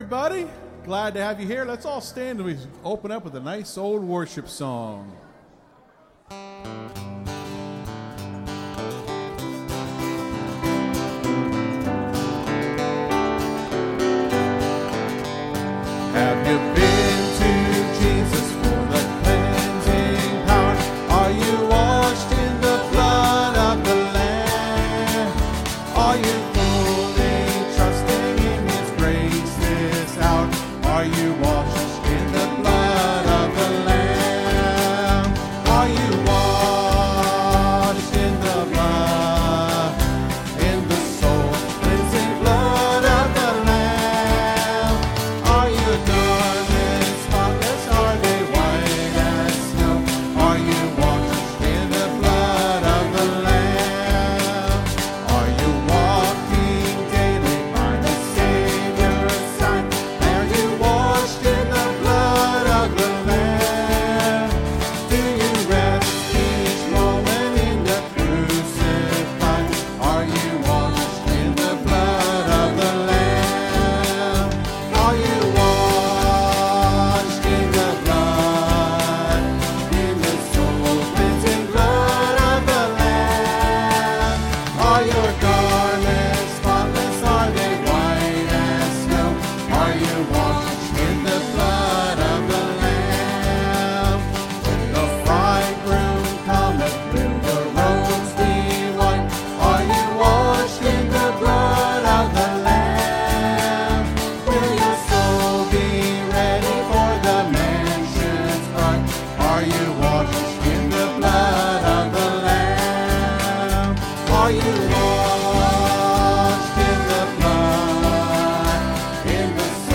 (Sermon starts at 30:10 in the recording).